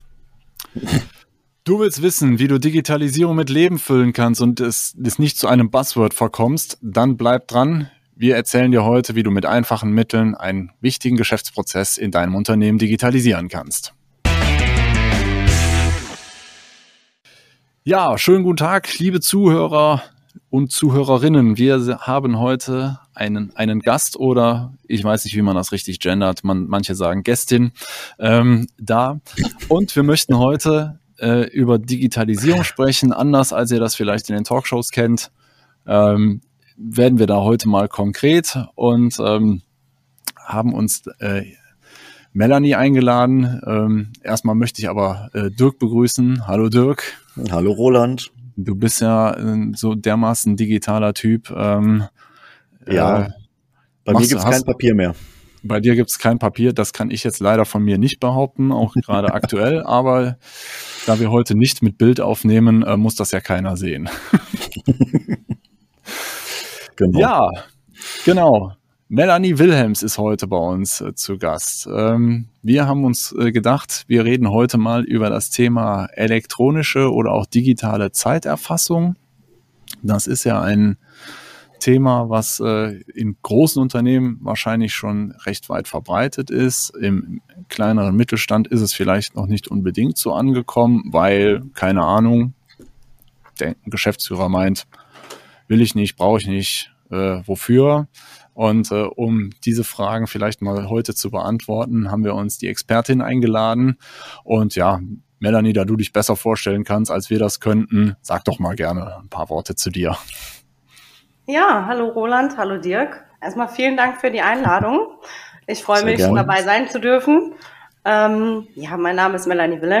Nr.33 So reduzierst du den bürokratischen Aufwand bei der Zeiterfassung. | Interview